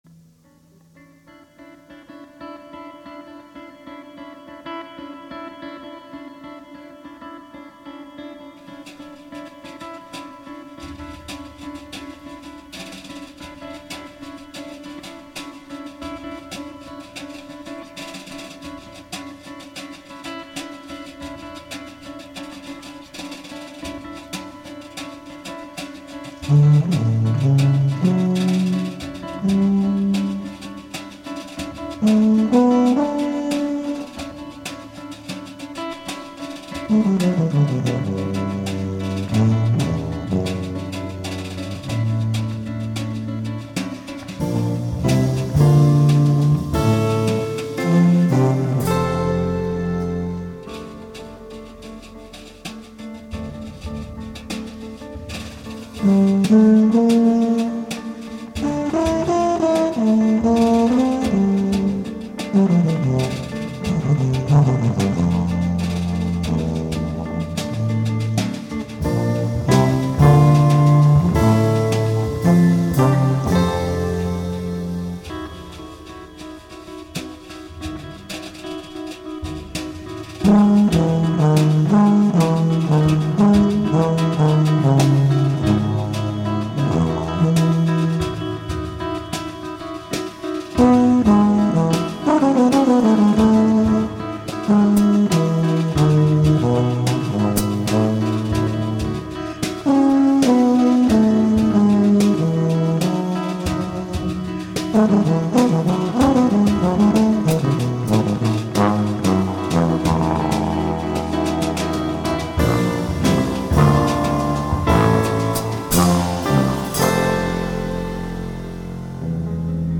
guitar
tuba, electric bass
drums